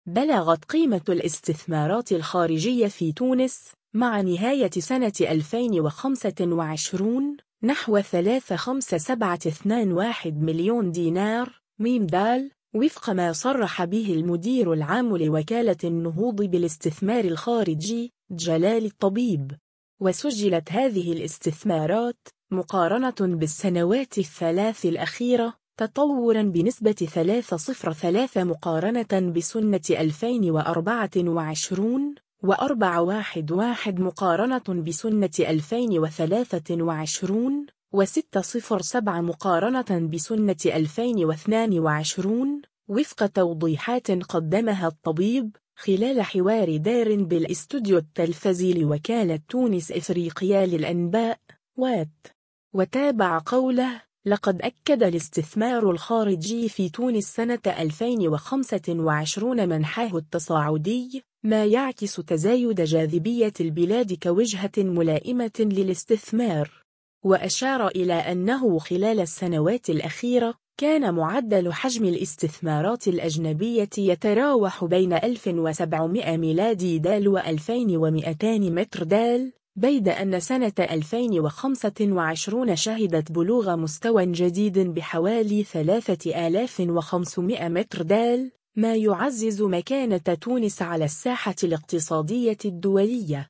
و سجلت هذه الاستثمارات، مقارنة بالسنوات الثلاث الأخيرة، تطورا بنسبة 30,3% مقارنة بسنة 2024، و41,1% مقارنة بسنة 2023، و60,7% مقارنة بسنة 2022، وفق توضيحات قدمها الطبيب، خلال حوار دار بالاستوديو التلفزي لوكالة تونس إفريقيا للأنباء (وات).